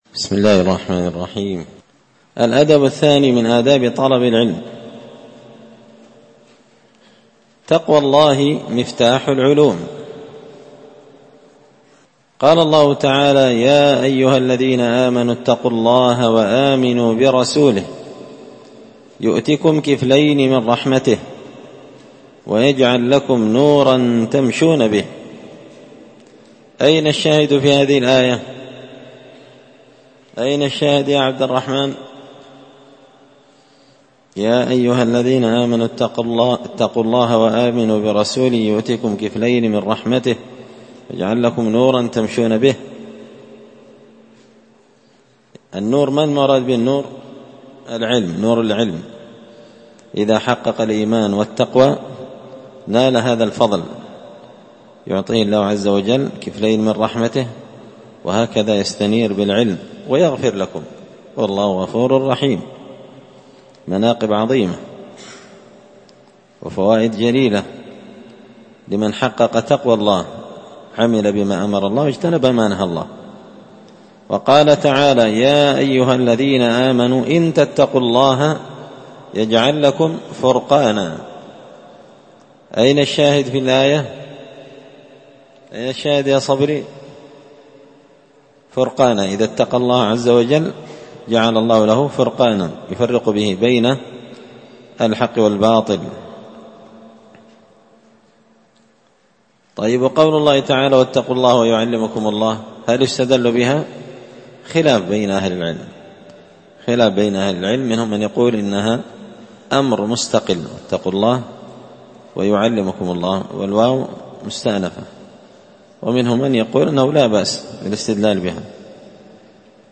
الدرس الثالث (3) الأدب الثاني تقوى الله مفتاح العلوم